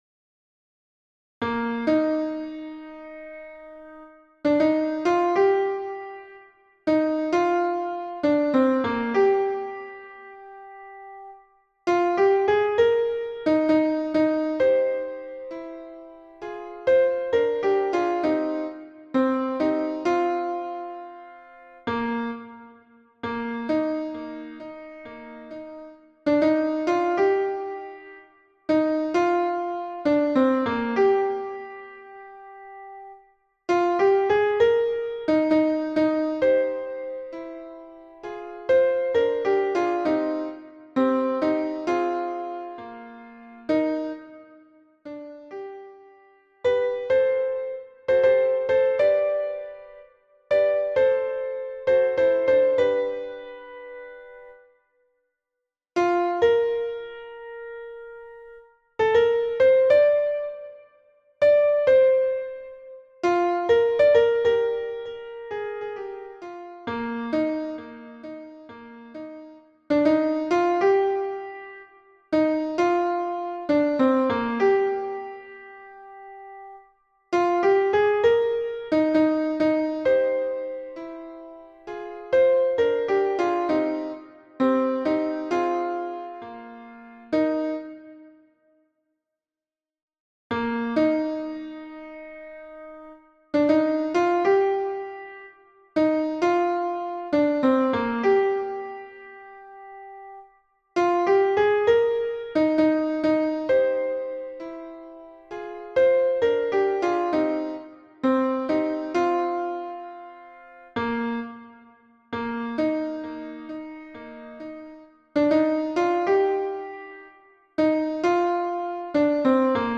Soprano et autres voix en arrière-plan